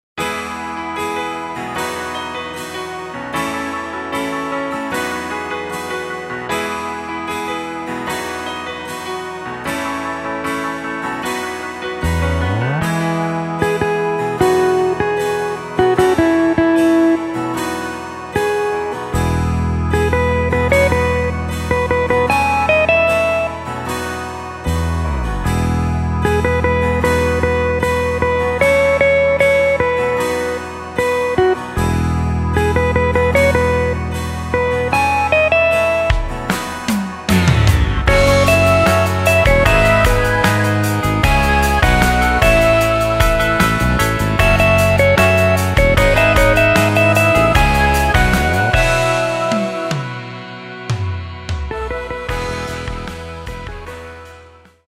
Takt:          2/4
Tempo:         76.00
Tonart:            E
Folk aus dem Jahr 1986!